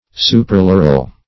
Meaning of supraloral. supraloral synonyms, pronunciation, spelling and more from Free Dictionary.
Search Result for " supraloral" : The Collaborative International Dictionary of English v.0.48: Supraloral \Su`pra*lo"ral\, a. (Zool.)